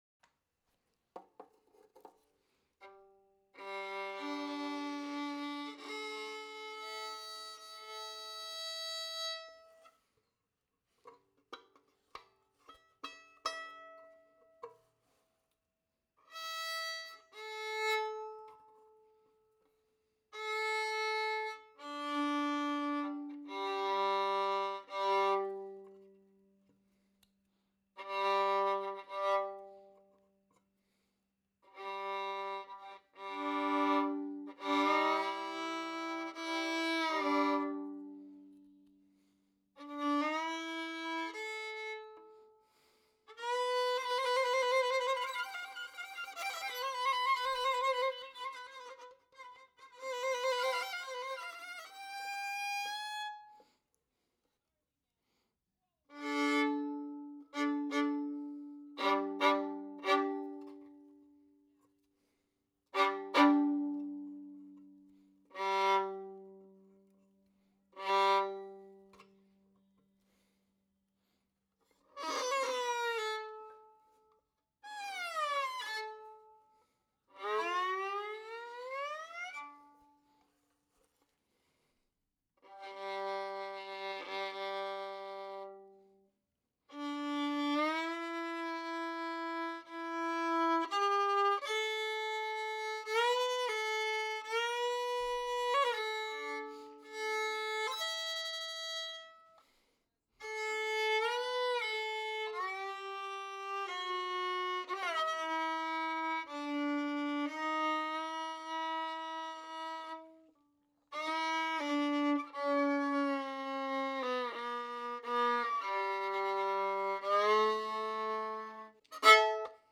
A Team - Fiddle_ - stems.wav